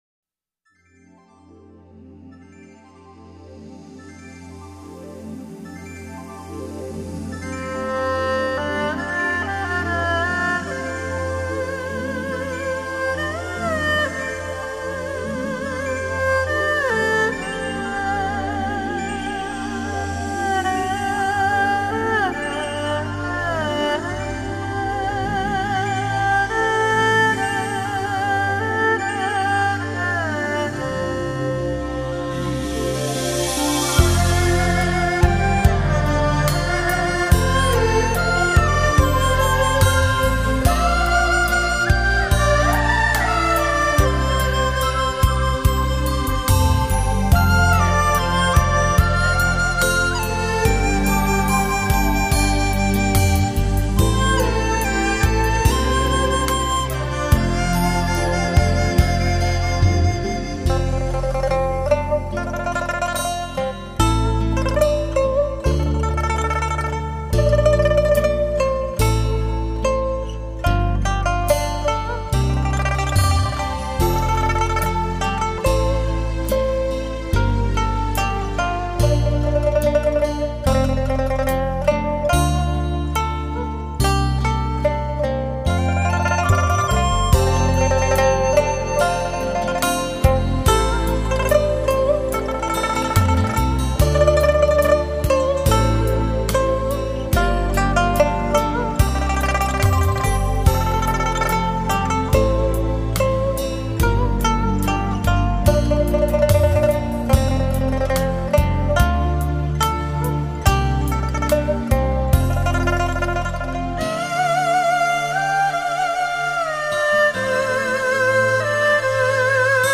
古筝＆二胡